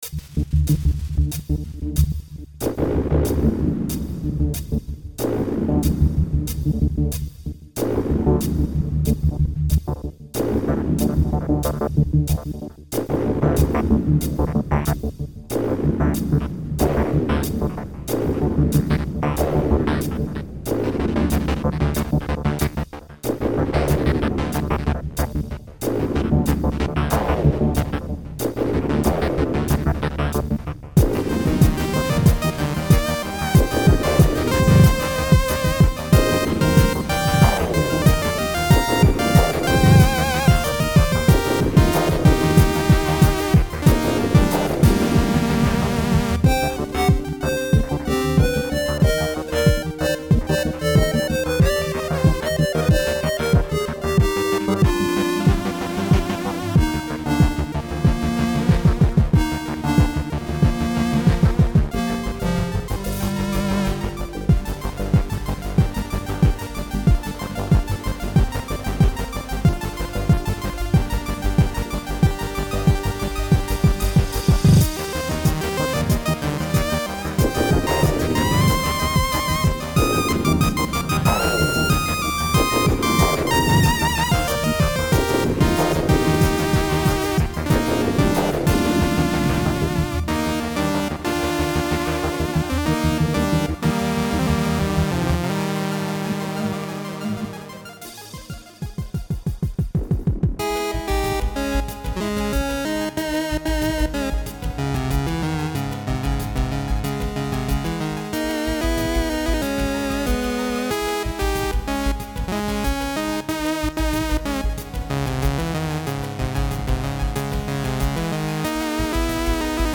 A chiptune that is the sequel to the original Raxi.